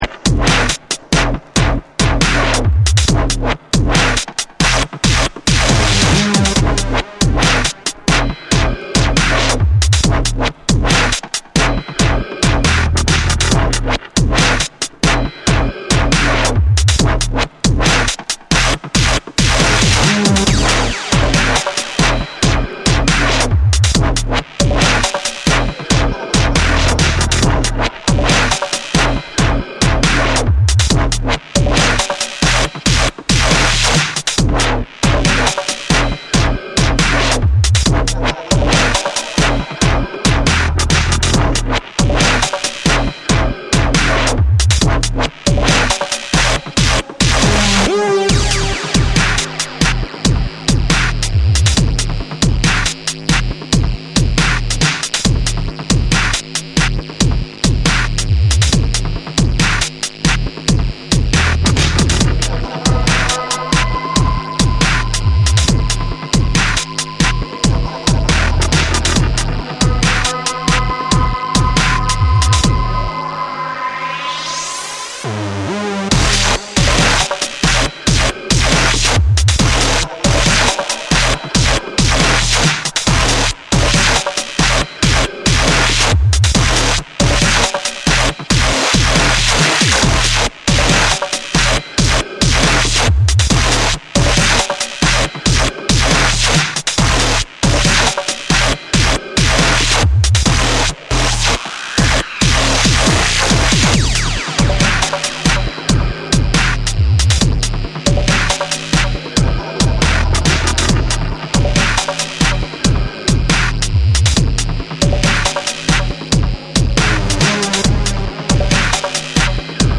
重厚なサブベースと緊張感のあるミニマルなビートが絡む、ストイックでダークなダブステップ
BREAKBEATS / DUBSTEP